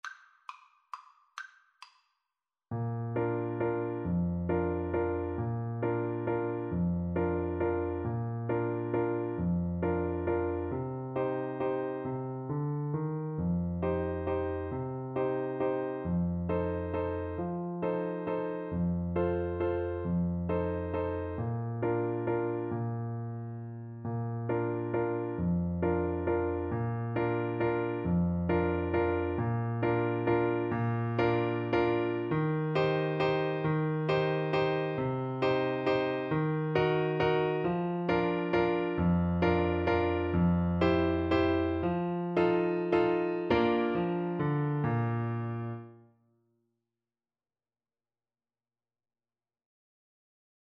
Waltz .=45
3/4 (View more 3/4 Music)
D5-F6
Classical (View more Classical Clarinet Music)